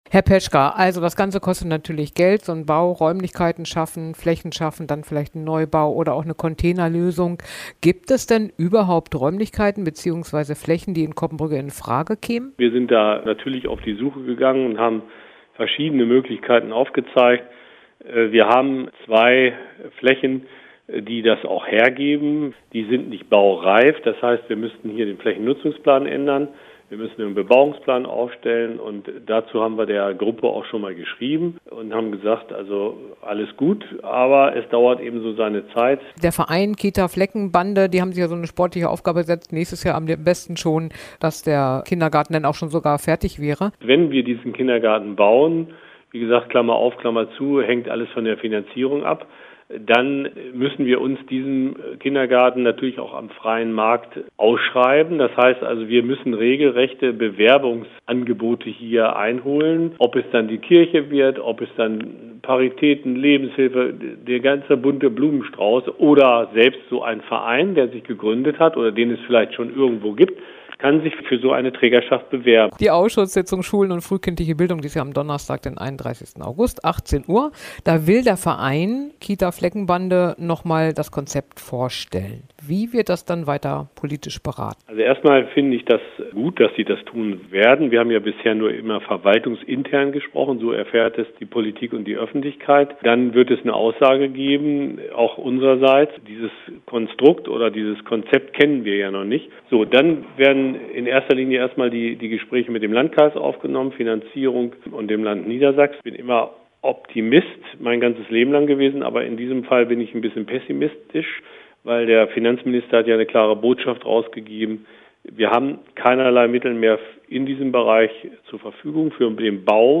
Coppenbrügge: Bürgermeister zur geplanten Kita „Fleckenbande“